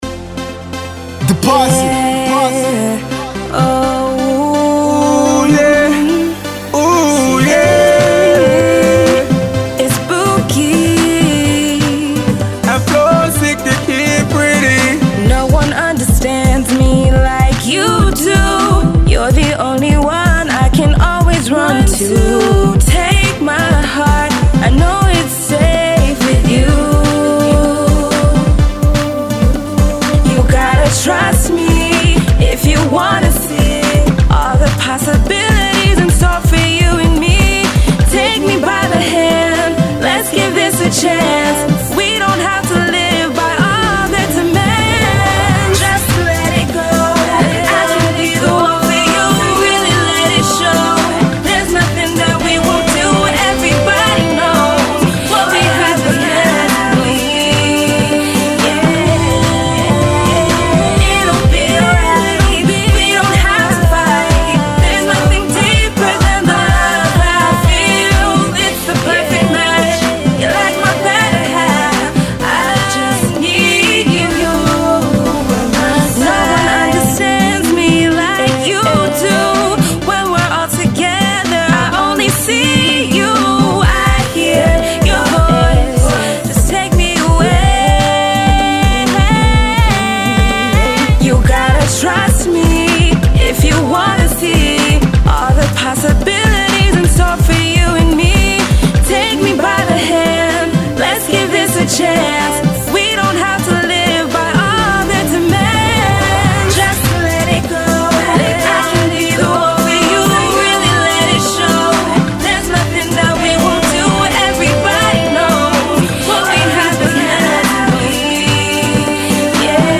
singer/songwriter with an amazing voice
the song makes for a potential club banger.